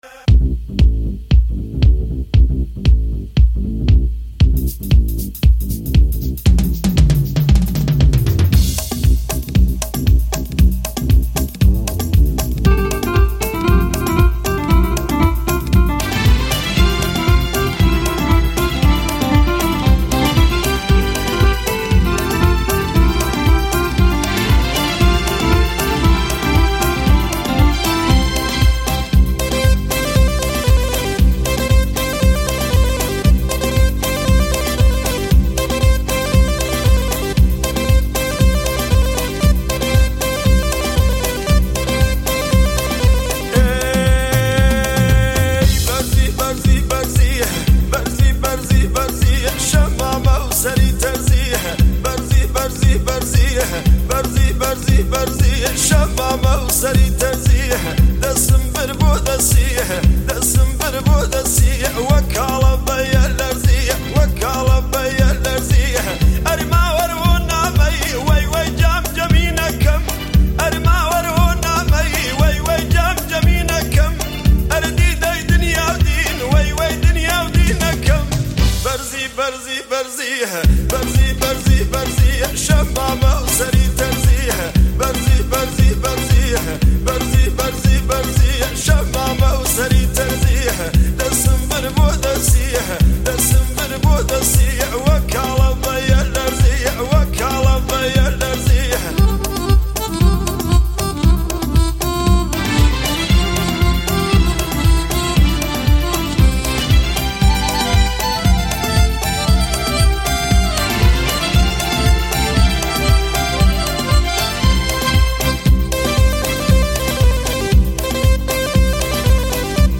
آهنگ کردی شاد قدیمی.